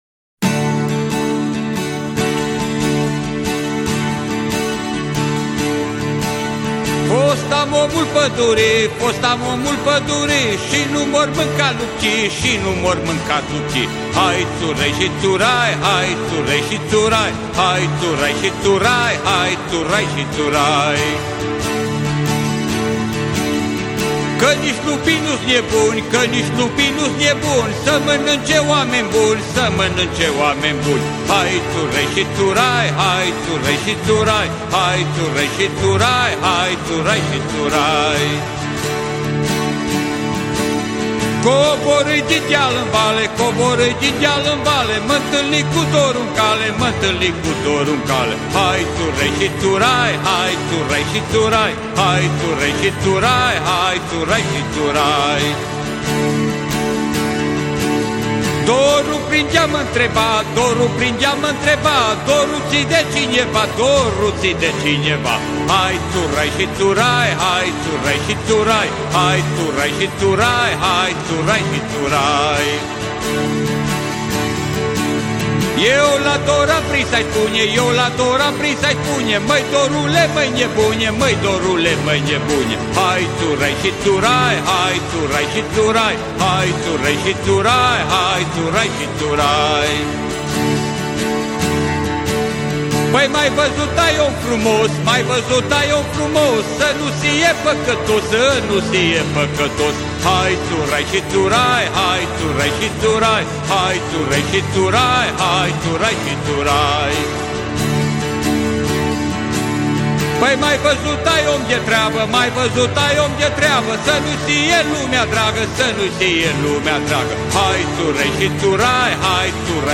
Muzica Unei Generaţii Perene - Aniversarea a 50 de ani de activitate artistică a chitaristului şi cantautorului de muzică folk DUCU BERTZI!
„Omul pădurii” (Prel. şi vers. populare, aranj. muz. Ducu Bertzi) de pe albumul „Dor de ducă” (C&P 1997 Intercont Music) interpretat de Ducu Bertzi.